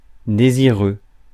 Ääntäminen
IPA : /əˈɡɒɡ/